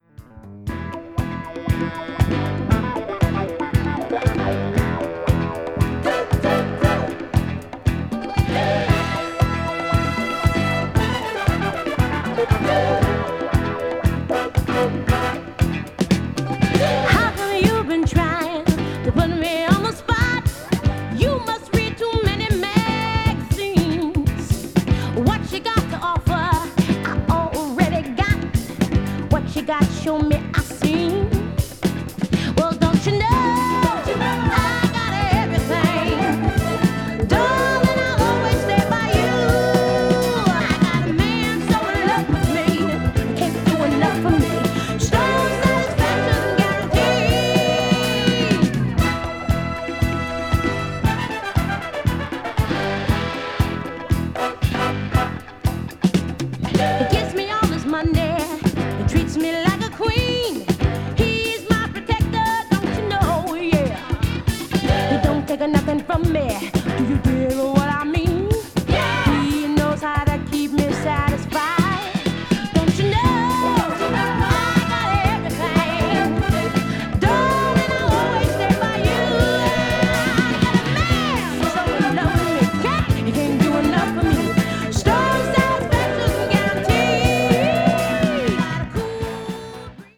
media : EX/EX(わずかにチリノイズが入る箇所あり)
ballad   disco related   funk   r&b   soul